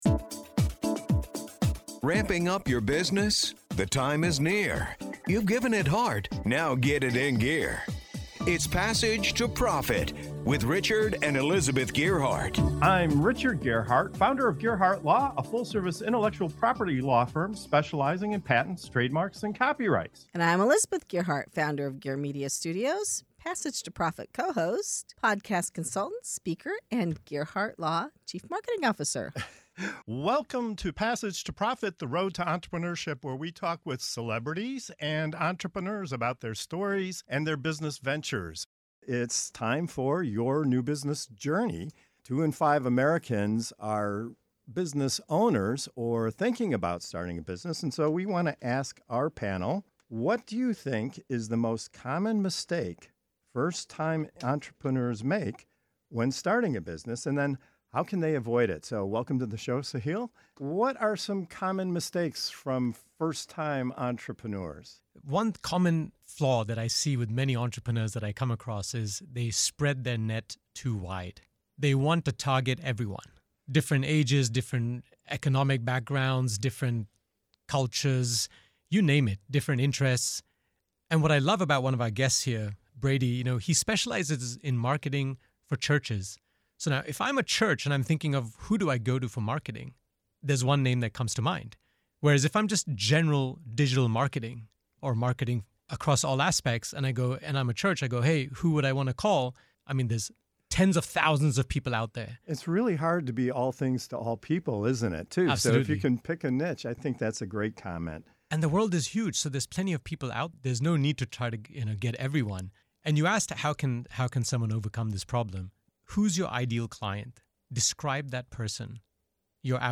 It’s a candid, practical conversation designed to help new and aspiring entrepreneurs build smarter, faster, and with far fewer regrets.